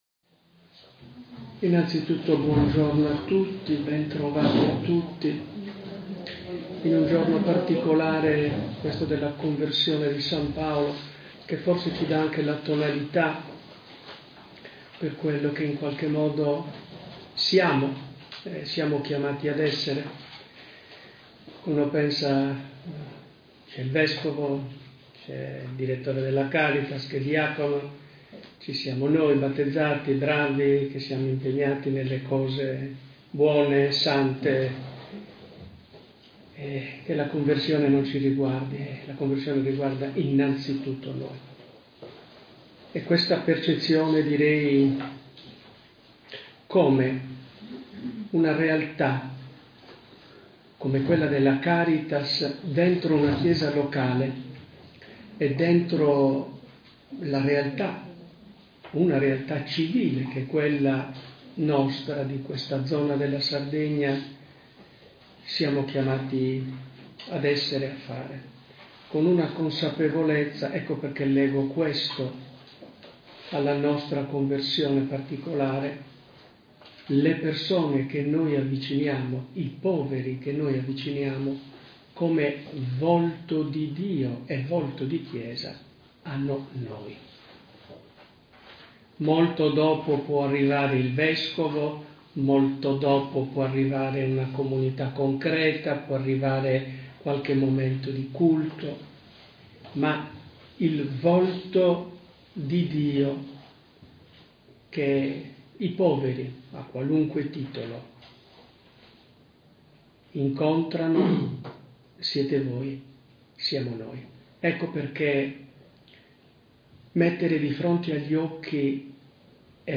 La Caritas diocesana, impegnata nell’opera pastorale caritatevole in vari ambiti e realtà della Chiesa di Alghero-Bosa, si è riunita Sabato 25 Gennaio ad Alghero per una giornata dedicata al confronto e alla formazione.
Rendiamo disponibile in allegato l’audio della relazione del Vescovo Mauro Maria Morfino intervenuto nel corso dell’incontro.